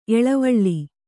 ♪ eḷavaḷḷi